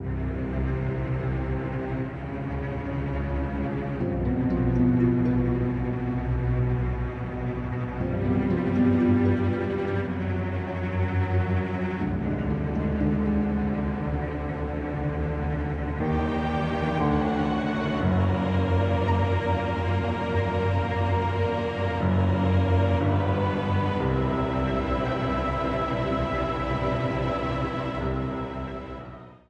Ripped from the game
clipped to 30 seconds and applied fade-out
This is a sample from a copyrighted musical recording.